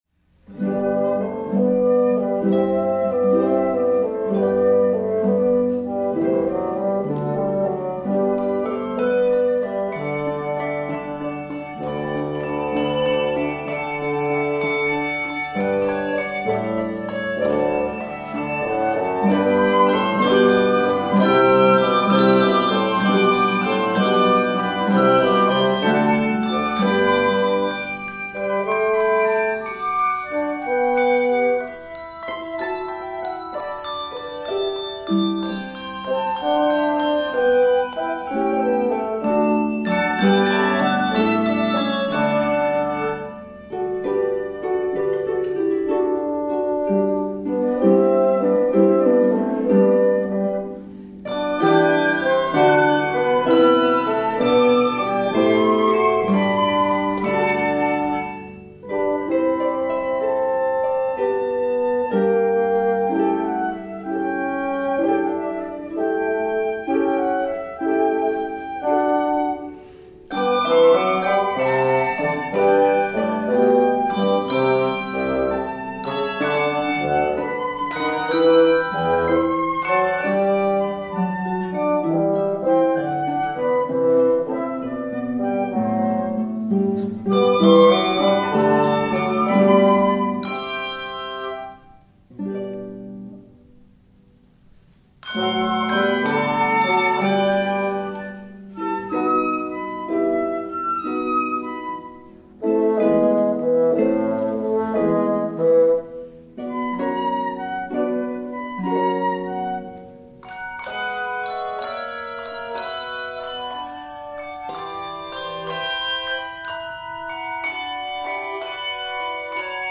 Key of G Major. 57 measures.
flute
bassoon
harp